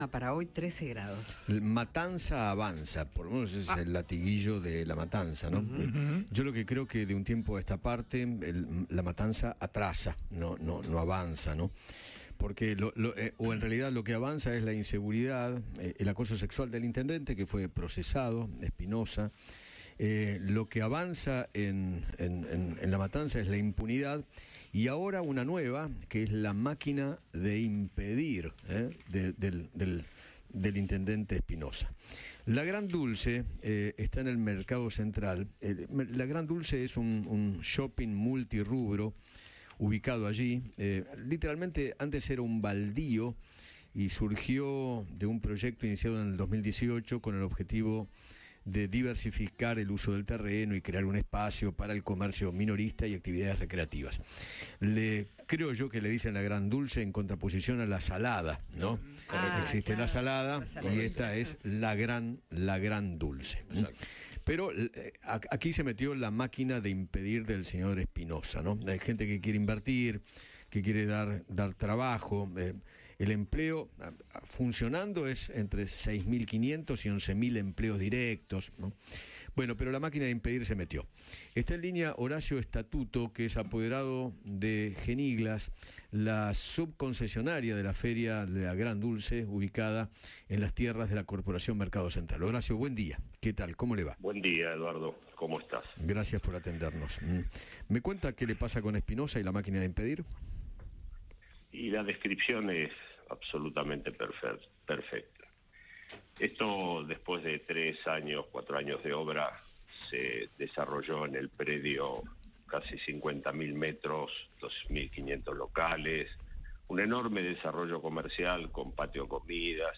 Eduardo Feinmann conversó con